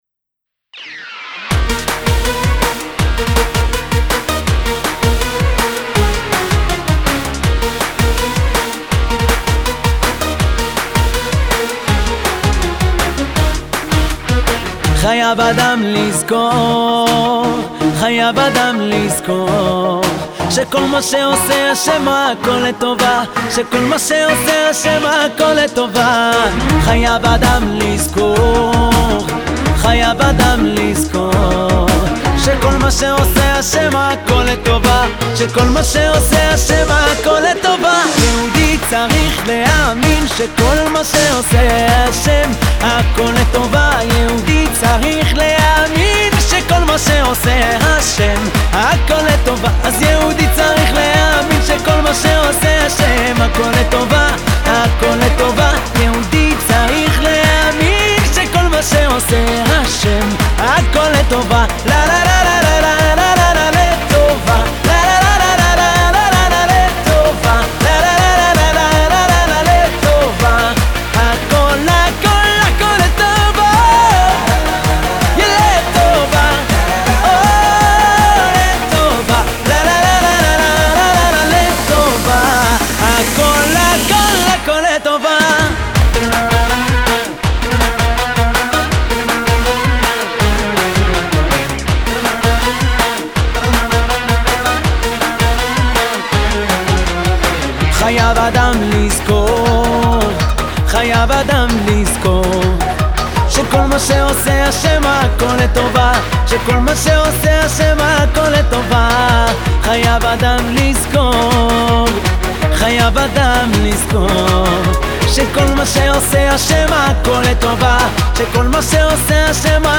השיר קיבל עיבוד חדשני